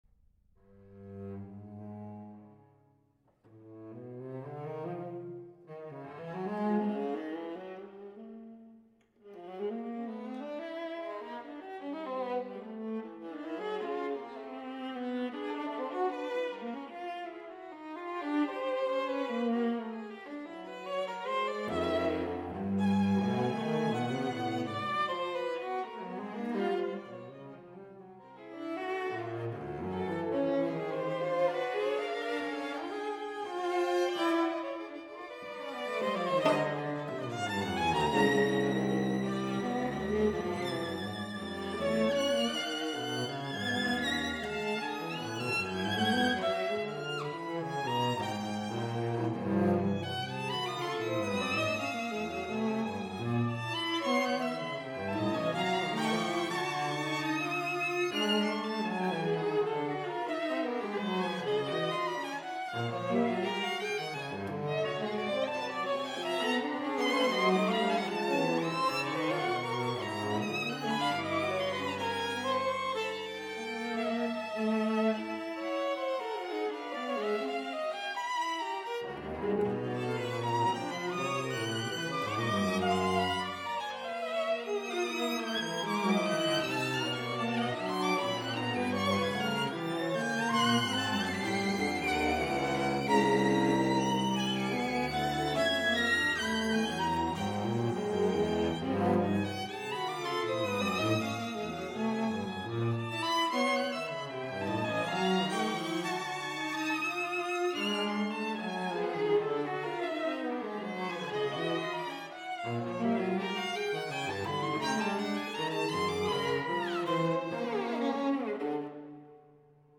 ALLEGRO MODERATO (235)